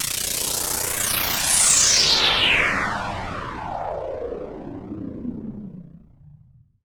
fxpTTE06003sweep.wav